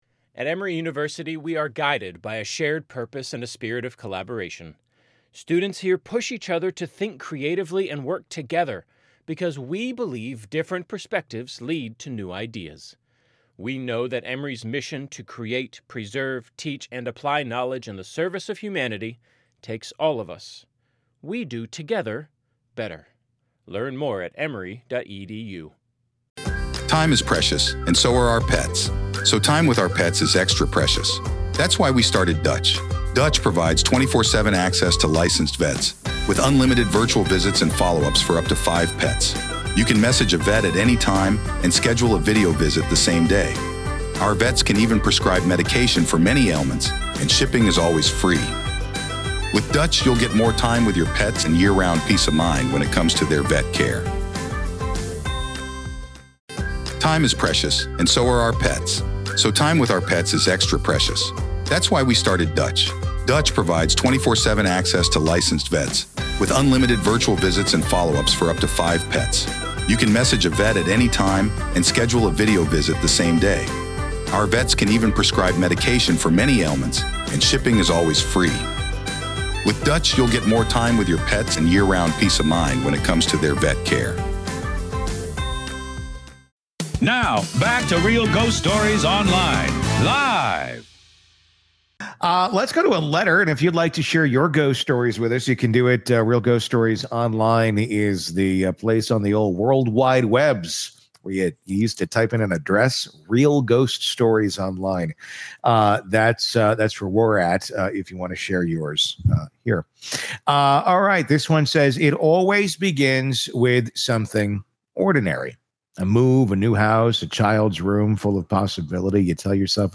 Real Ghost Stories LIVE